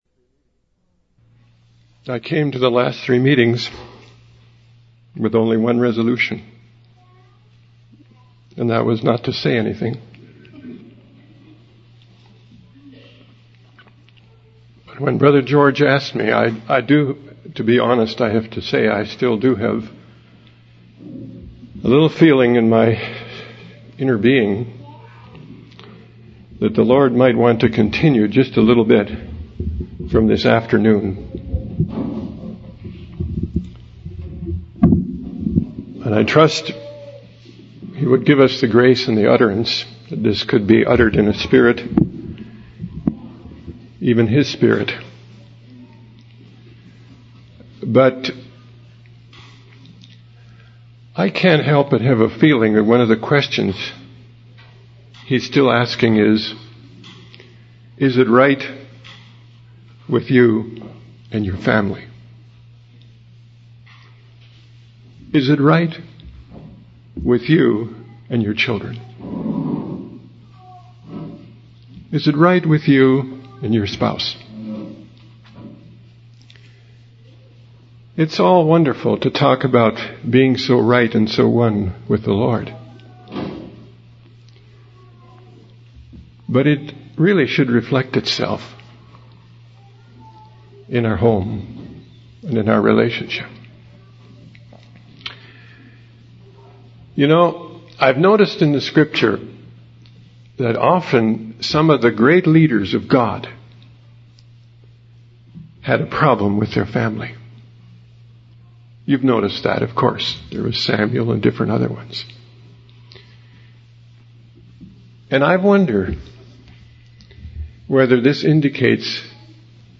In this sermon, the speaker reflects on the state of the hearts of believers and their longing for God to move in their lives.